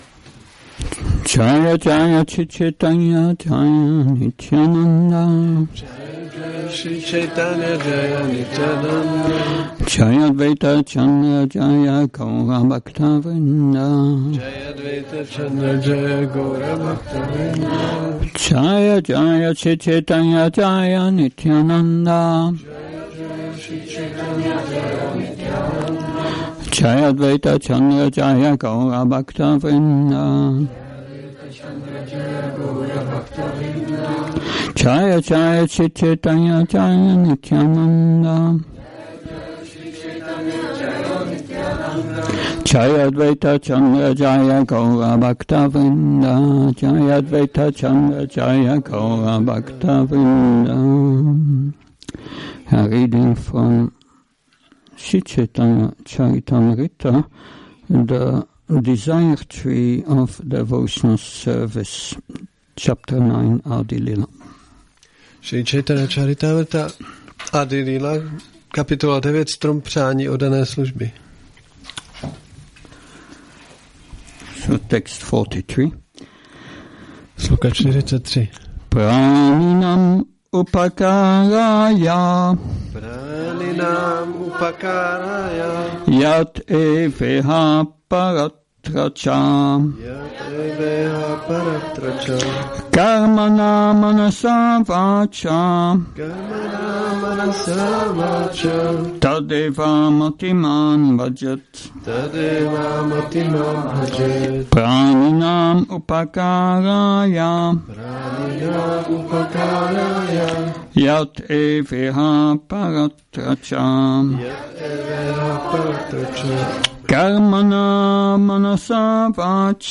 Přednáška CC-ADI-9.43 – Šrí Šrí Nitái Navadvípačandra mandir